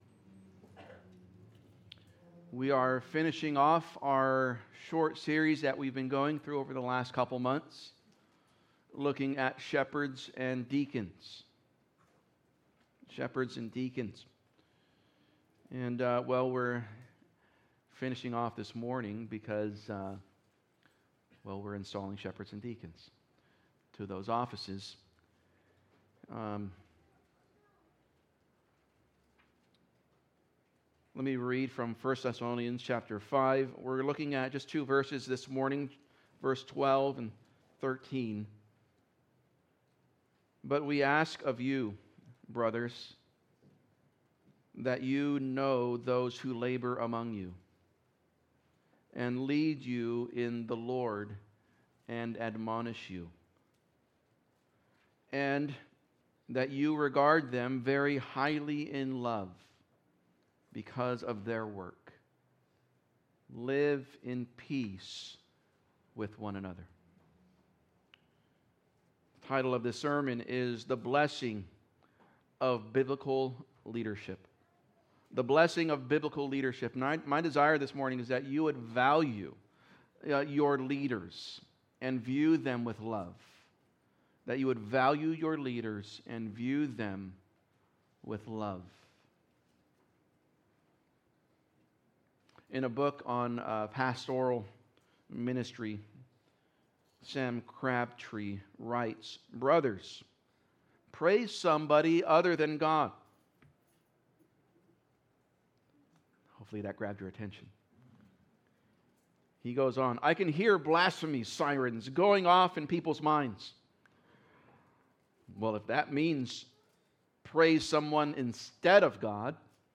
Sermon Info: Title: The Blessing of Biblical Leadership Series: Shepherds & Deacons Passage: 1 Thessalonians 5:12-13 Outline: 1. The Value of Leaders 2. Our View of Leaders